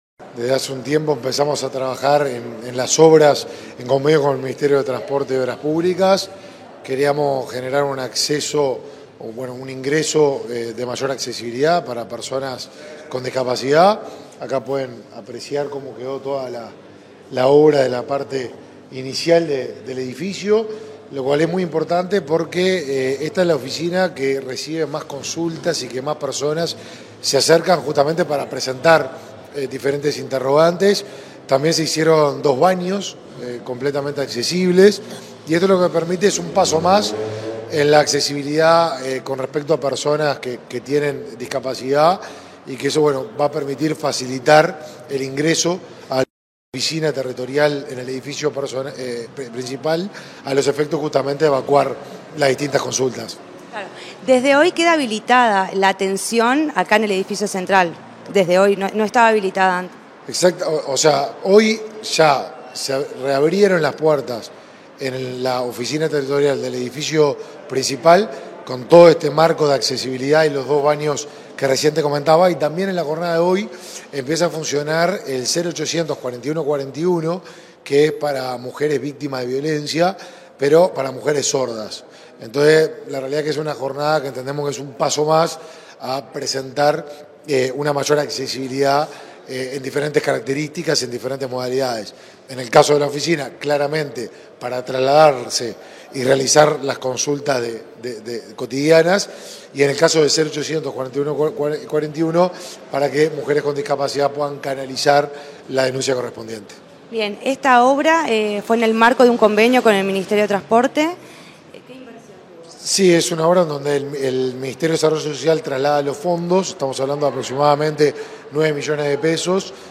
Entrevista al ministro del Mides, Martín Lema, y a la directora de Discapacidad, Karen Sass
El titular del Ministerio de Desarrollo Social (Mides), Martín Lema, y la directora de Discapacidad del Mides, Karen Sass, realizaron una recorrida, este 12 de diciembre, por las remozadas instalaciones del edificio central de la referida entidad. Luego, ambos jerarcas realizaron declaraciones a Comunicación Presidencial.